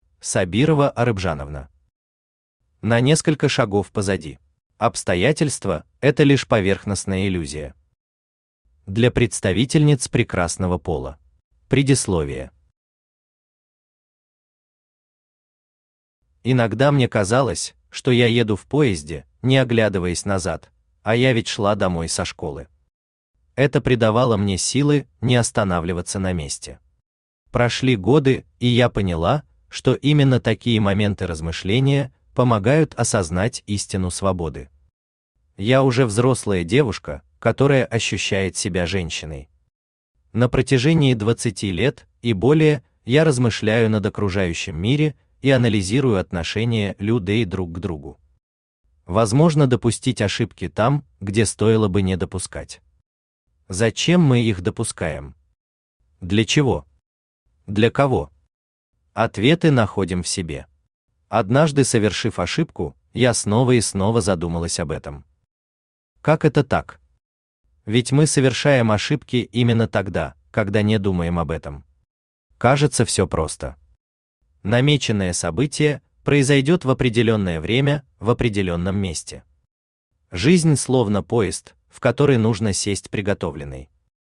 Аудиокнига На несколько шагов позади | Библиотека аудиокниг
Aудиокнига На несколько шагов позади Автор Сабирова Зулейхан Арыпжановна Читает аудиокнигу Авточтец ЛитРес.